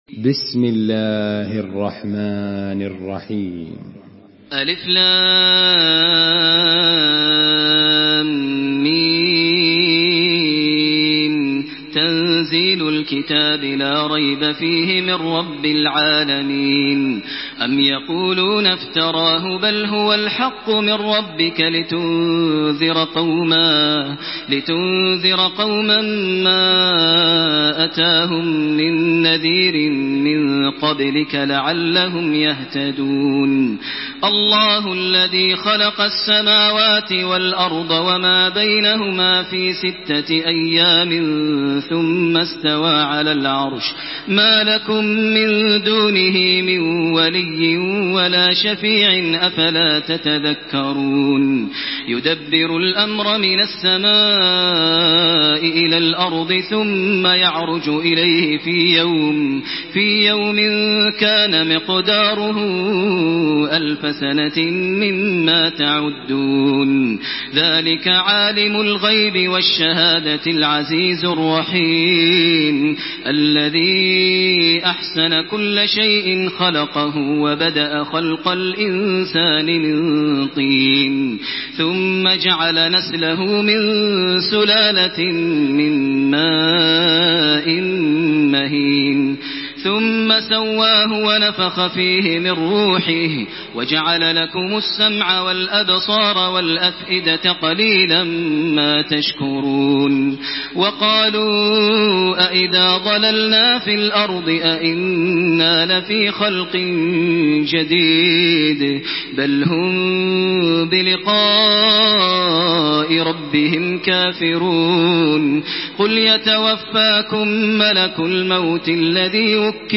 Makkah Taraweeh 1428
Murattal